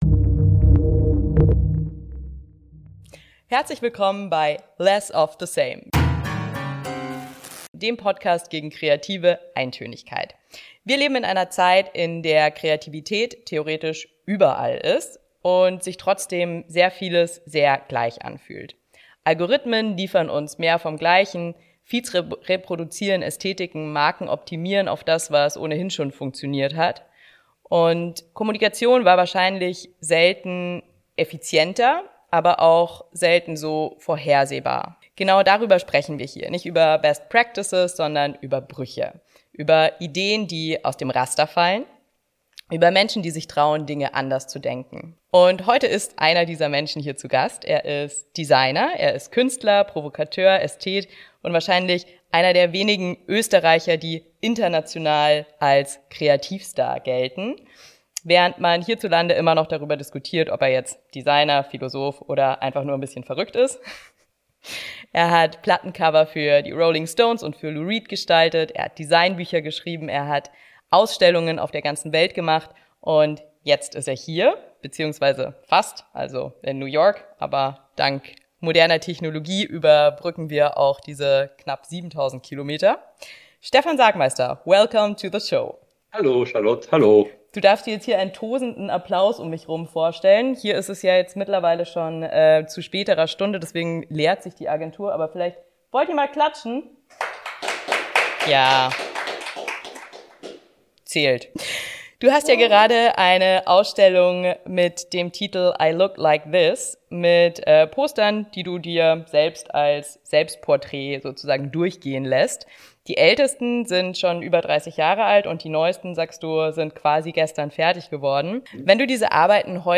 Sagmeister spricht über seine Sabbaticals in New York, Indonesien, Mexiko, Madrid und den österreichischen Alpen – mal ohne Plan, mal mit strengem Stundenplan, immer als Versuch, Routinen zu unterbrechen und Platz für neue Gedanken zu schaffen.